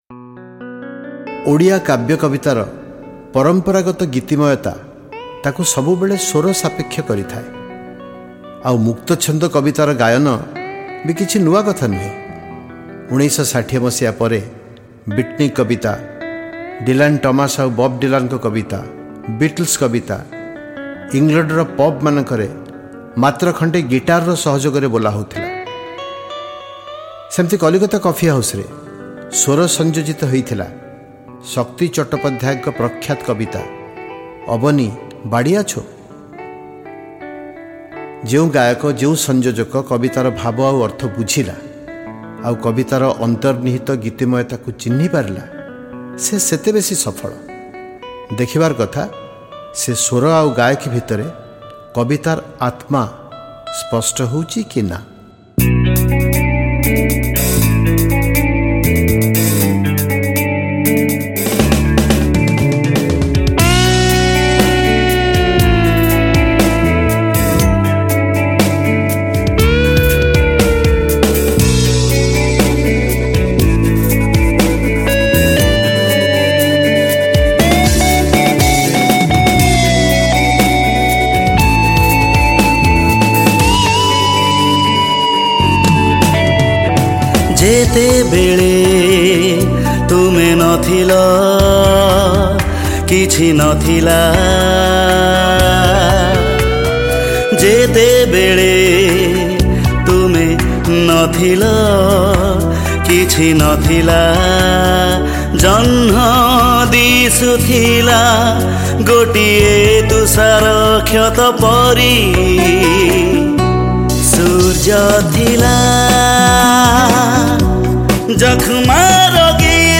Category: Blue Mood